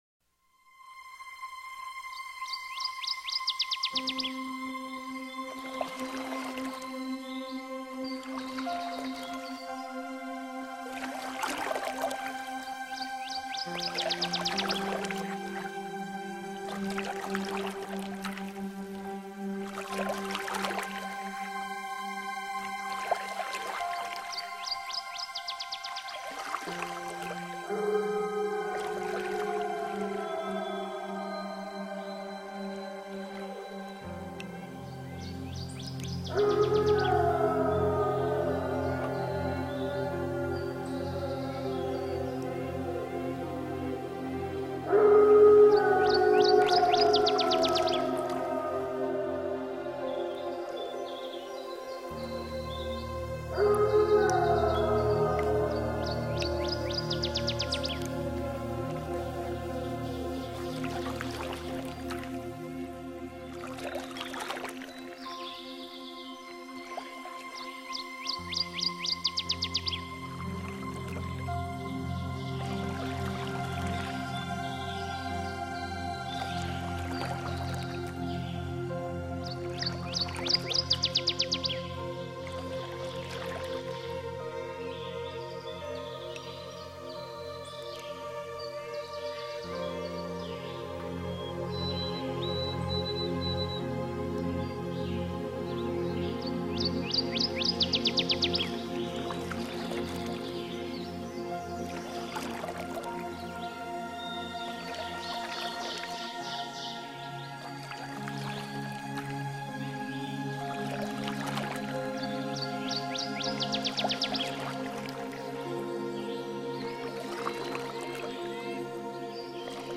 MUSICA AMBIENTAL  RELAJANTE _ NATURALEZA VIRGEN.mp3